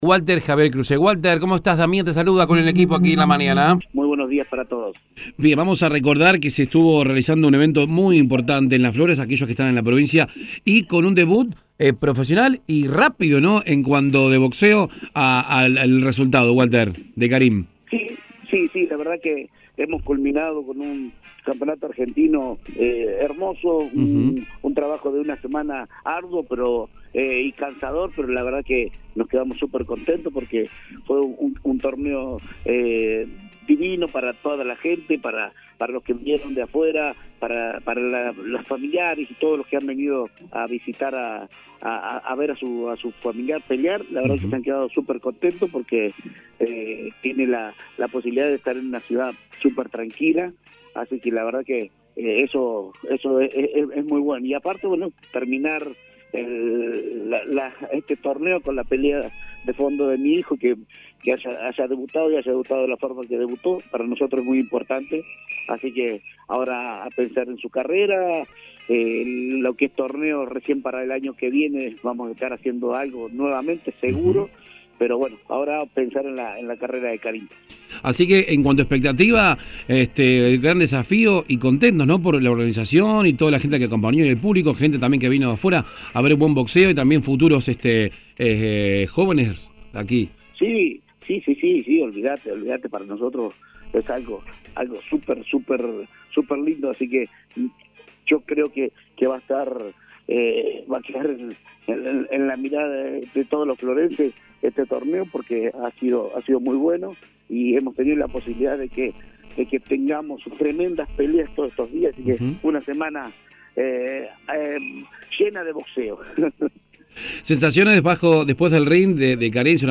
En una breve comunicación telefónica